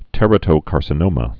(tĕrə-tō-kärsə-nōmə)